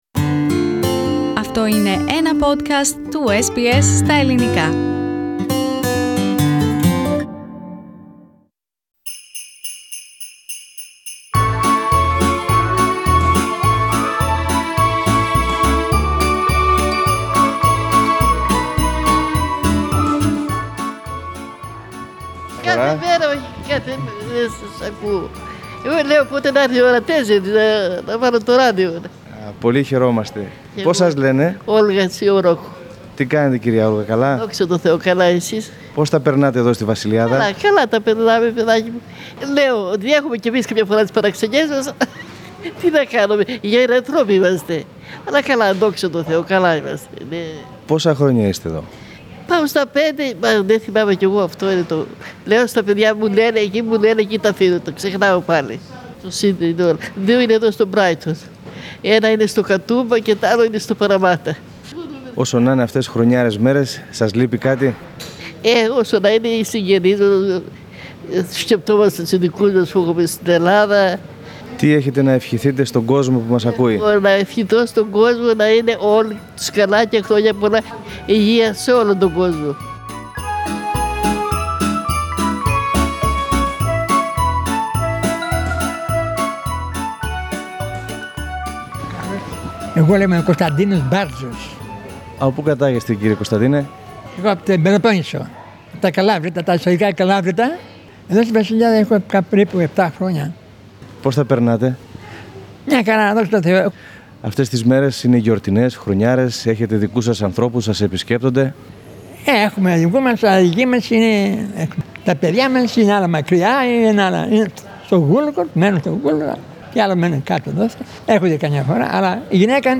Greek migrants, now in their advanced age, who are guests at St Basils Home for the elderly in Sydney’s Lakemba suburb, send their wishes and their festive message.